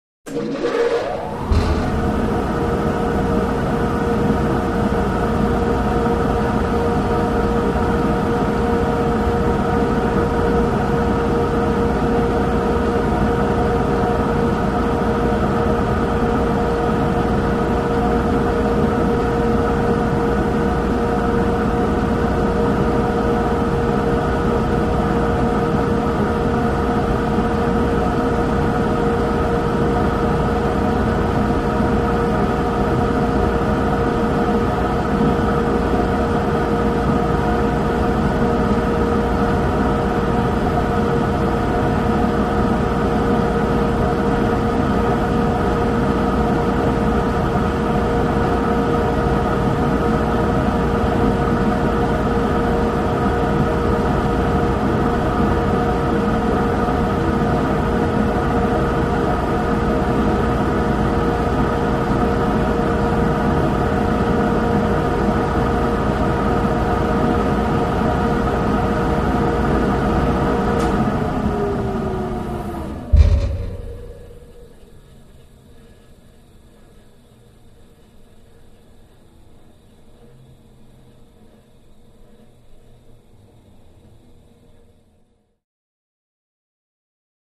Industrial Fan | Sneak On The Lot
Warehouse Fan; Large Industrial Fan; On / Steady / Off / Slow Wind Down, Loud Low Frequency Clunk When Turned Off, Close Perspective.